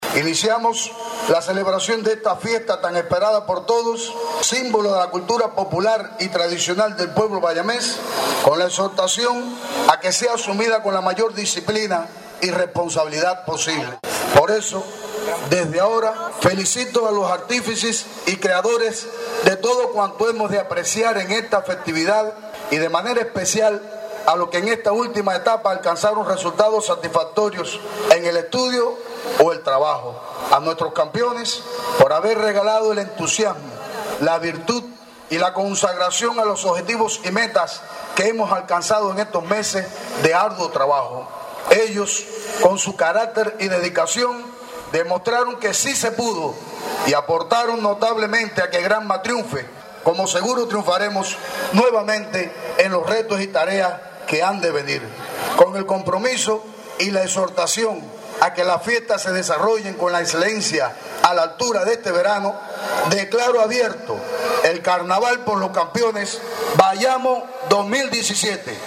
Ante la presencia de miles de bayameses abrió en la noche de este miércoles la mayor festividad popular de la ciudad con el desarrollo de la gala Carnaval por los campeones.
Intervención de Samuel Calzada Deyundé, presiden de la Asamblea municipal del Poder Popular
Intervencion-de-Salmuenl-Calzada-Deyund---presiden-de-la-Asamblea-municipal-del-Poder-Popular.mp3